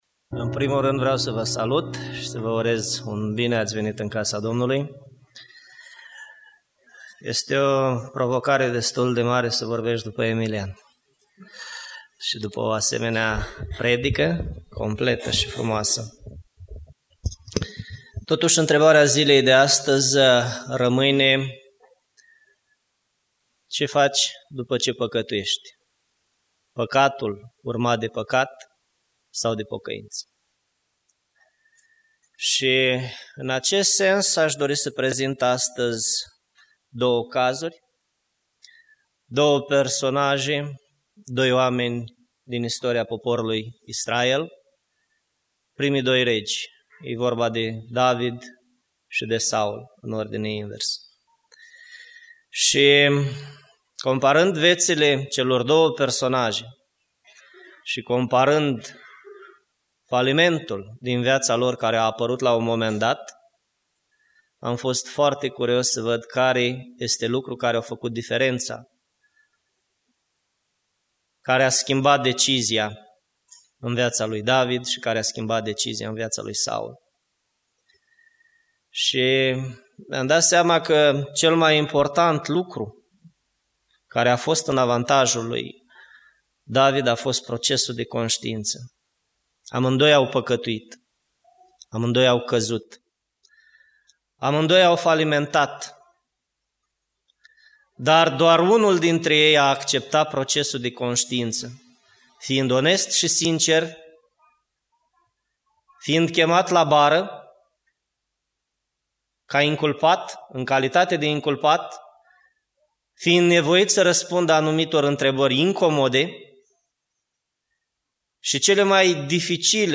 Predica Aplicatie - Saul, David si procesul de constiinta